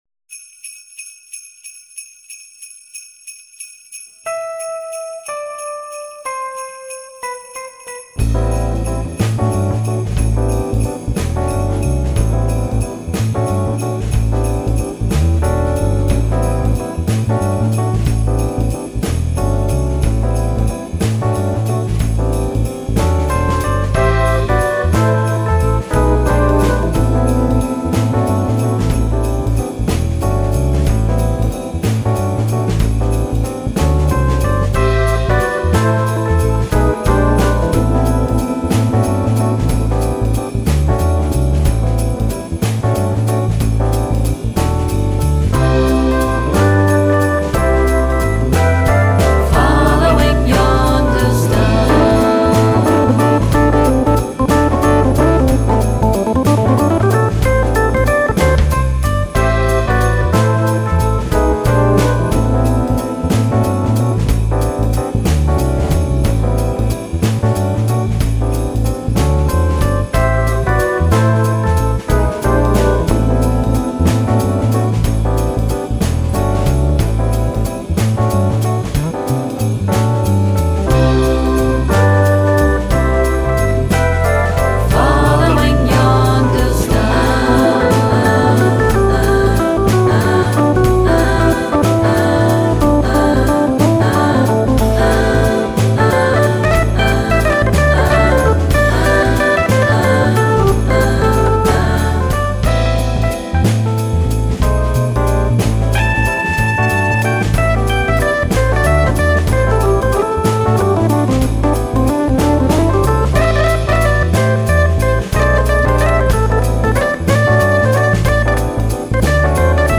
Brian Auger a enregistré un petit morceau en guise de "bon noël" et le diffuse (et le fait diffuser par les bien-pensants, d'où mon empressement flagorneur) chez ses fans, sympathisants, programmateurs, acheteurs de disques potentiels, et autres.
2- le blaireau qui a encodé ou retranscris ou que sais-je cela a aucune notion du niveau ou des réglages minimum, et le truc est saturé cradouillat, façon MyOverdrivePedal des gratteux boutonneux pré-ados qu'on voit collé sur les vitrines des magasins de zik'...
Puis depuis sa version de All blues ça faisait longtemps qu'il avait pas fait sur 3 temps.